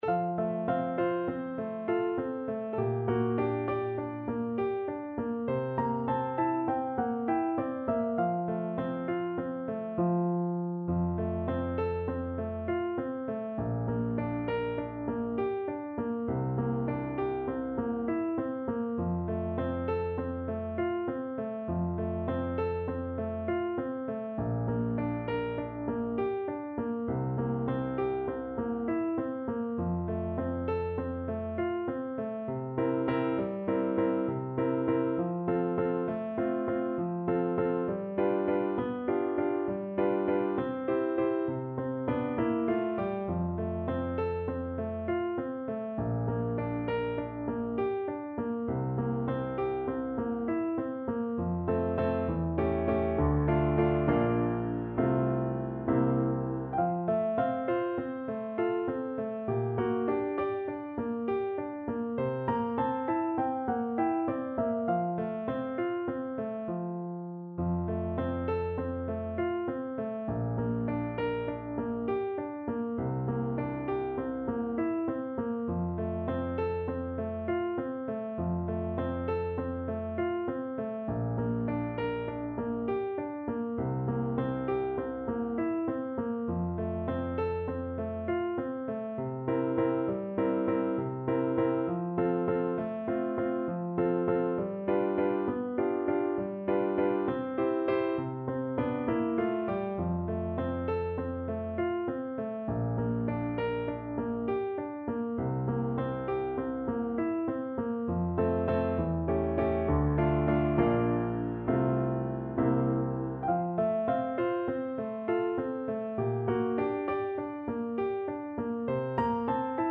Play (or use space bar on your keyboard) Pause Music Playalong - Piano Accompaniment Playalong Band Accompaniment not yet available transpose reset tempo print settings full screen
Moderato
F major (Sounding Pitch) (View more F major Music for Bassoon )
9/8 (View more 9/8 Music)